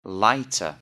lighter.mp3